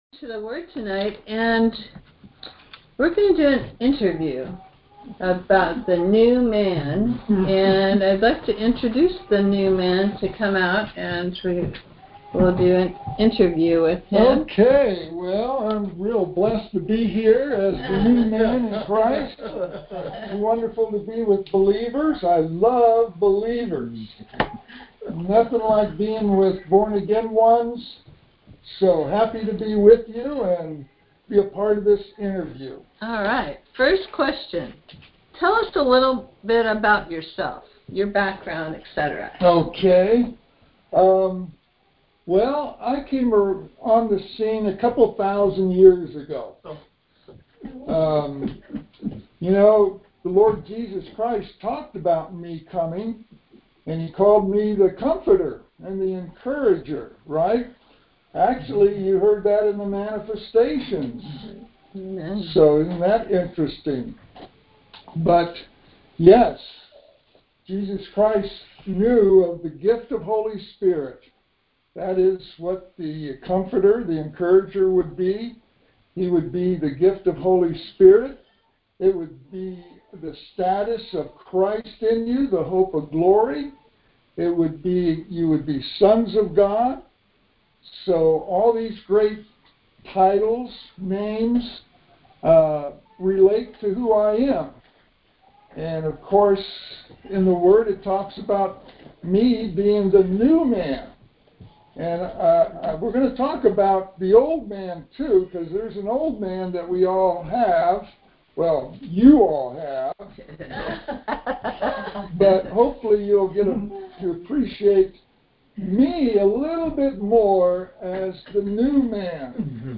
Conference Call Fellowship Date